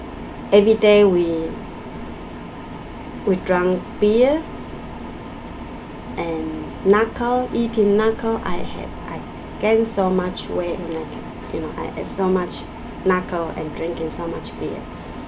S1 = Malaysian female S2 = Taiwanese female Context: S2 is talking about some time she spent in southern Germany.